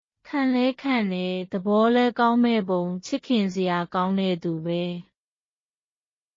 カンレーカンレ　ダボーレカウンメーポ　チッキンセヤーカウンベートゥーベ
当記事で使用された音声（日本語およびミャンマー語）はGoogle翻訳　および　Microsoft Translatorから引用しております。